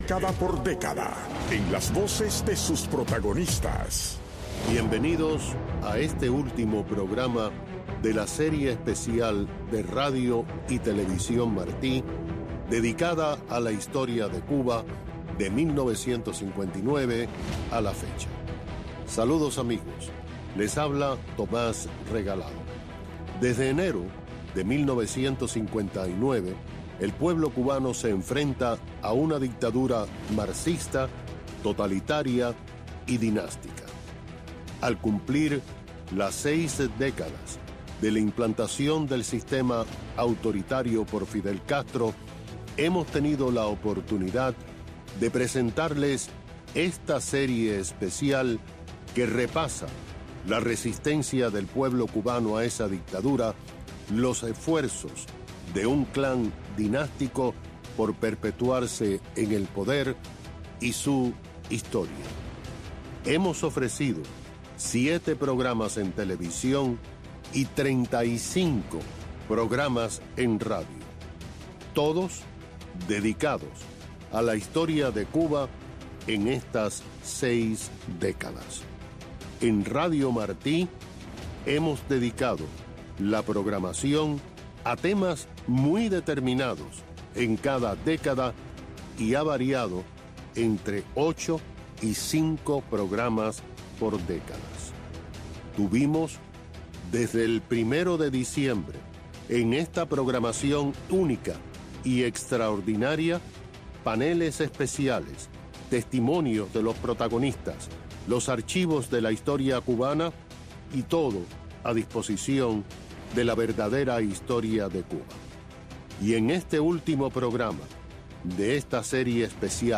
Moderador Tomas Regalado Invitado